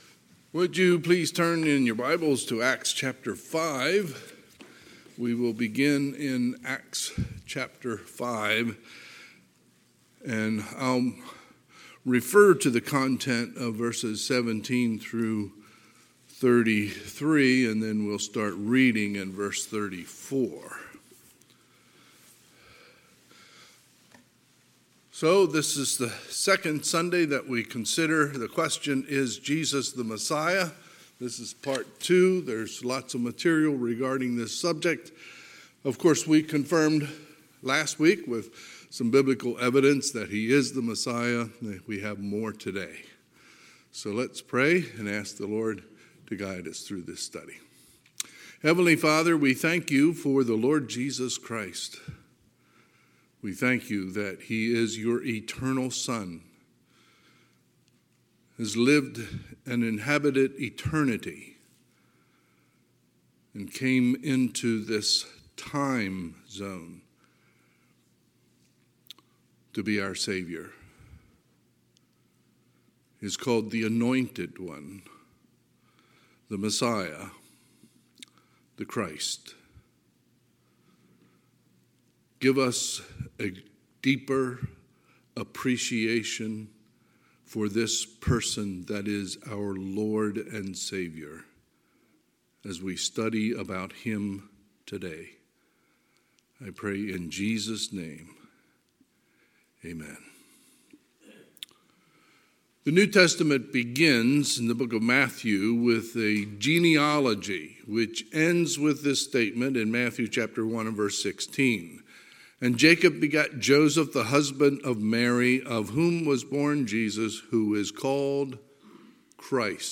Sunday, December 11, 2022 – Sunday AM
Sermons